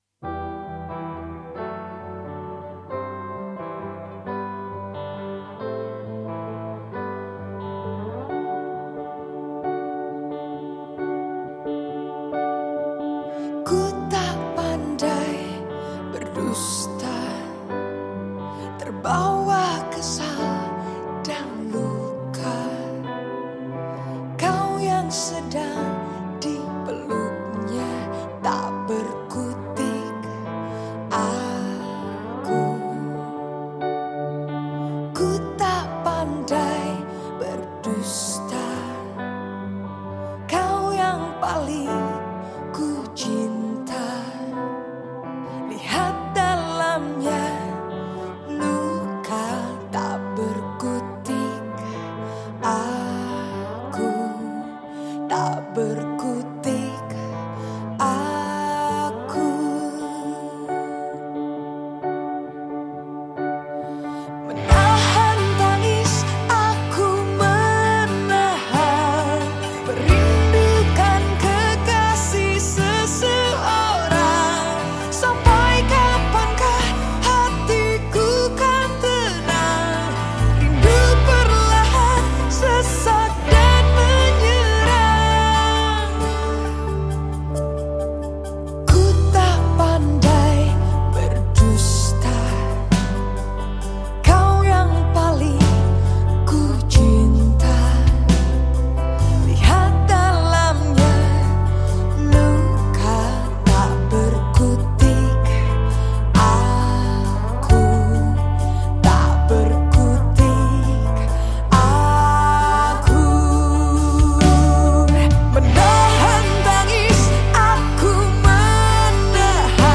Genre Musik                   : Rock